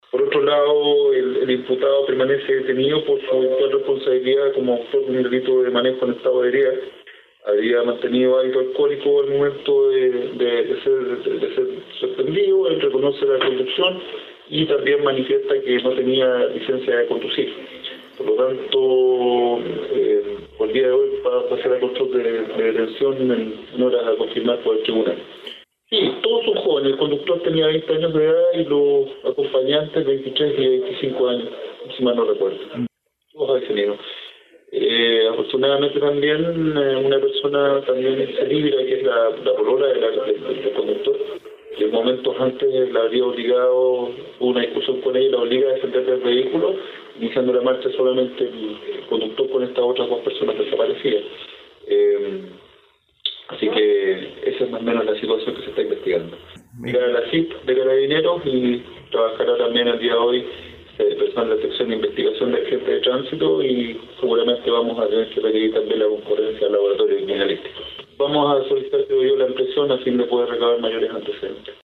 El persecutor indicó que el conductor permanece detenido preliminarmente por conducción en estado de ebriedad, además de no tener licencia de conducir, y que en la audiencia de este lunes, se pedirá la ampliación de la detención para recabar más antecedentes del caso.